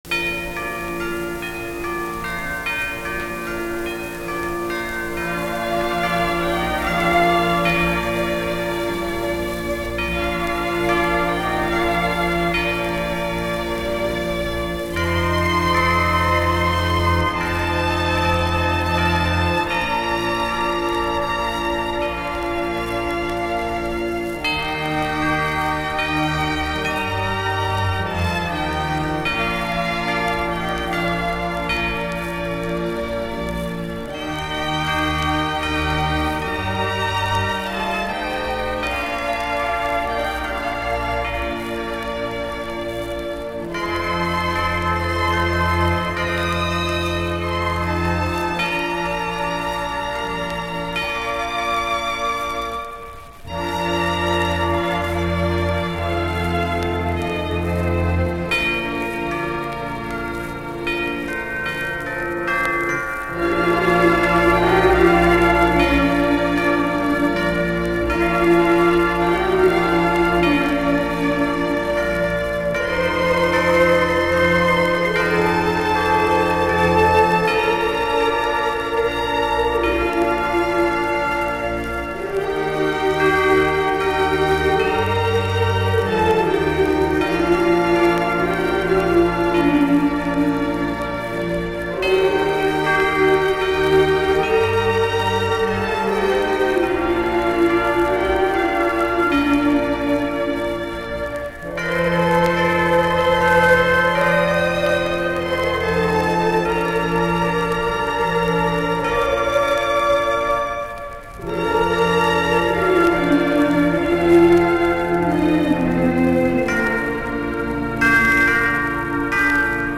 Pladen, som udgør midten af julekalenderen, skal afspilles på 45 omdrejninger i minuttet.
Sangen på pladen blev sunget af et unavngivet københavnsk børnekor og indspillet i København.
Som man kan høre på indspilningen er der først et instrumentalt vers og derefter et vers, som nynnes uden ord af et børnekor.